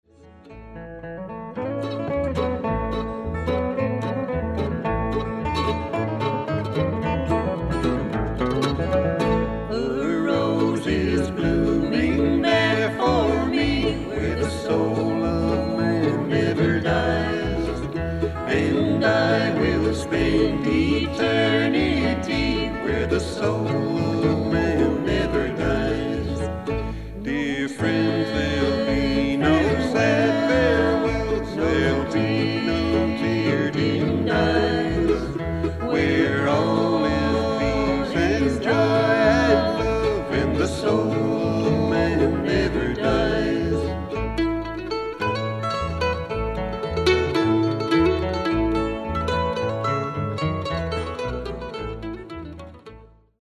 An elcectic collection of original songs including popular and traditional acoustic country music and ballads.
Traditional
Recorded at "TzoraFolk Home Studio"